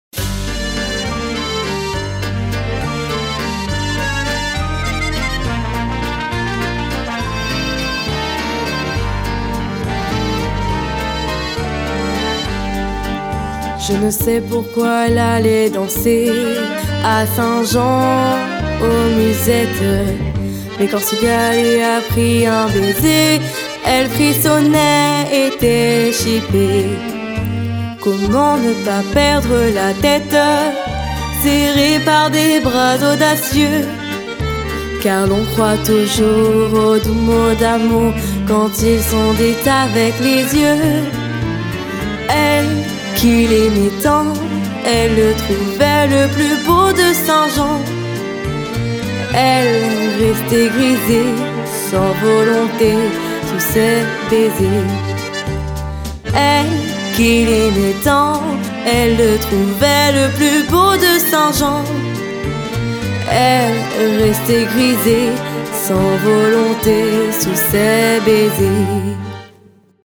La version chantée